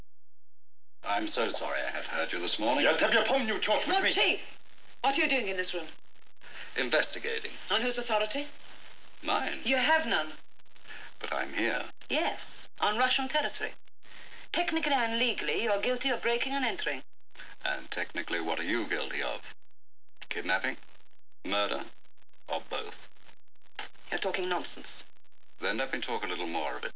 Simon Turns the Argument Around... (Episode: "The Russian Prisoner")